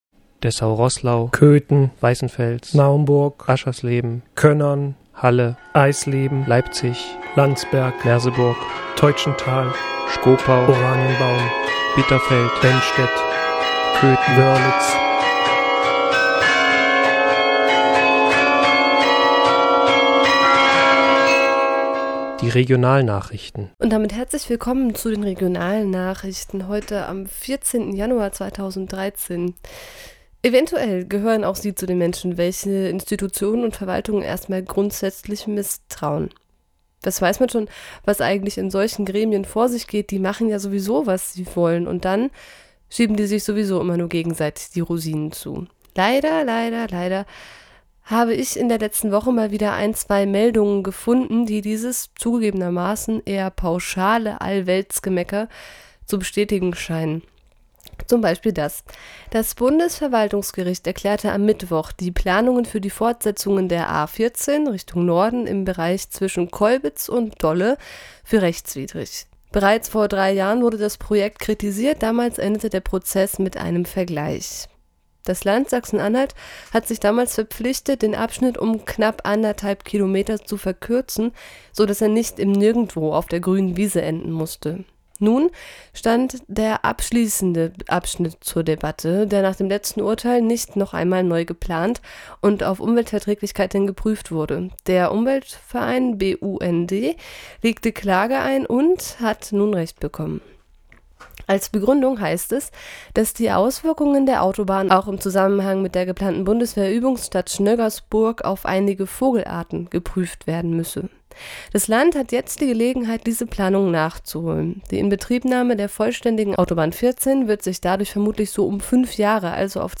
Regionalnachrichten vom 14. Januar 2014